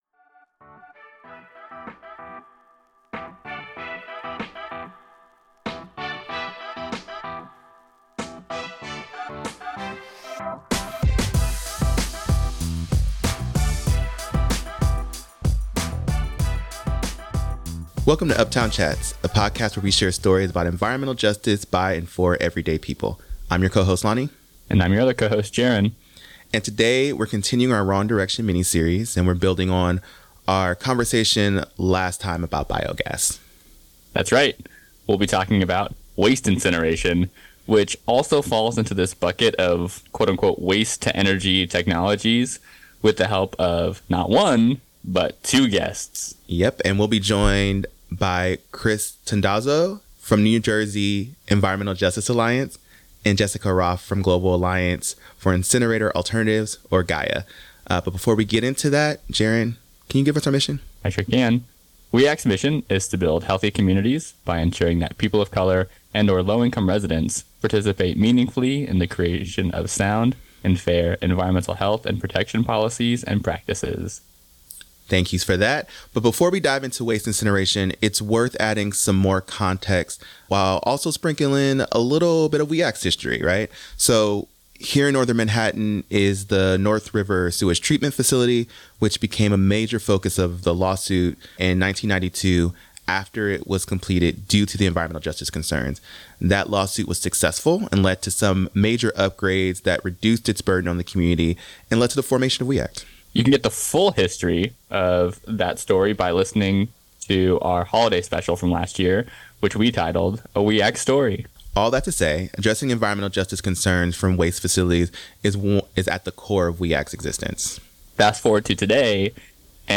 with the help of two special guests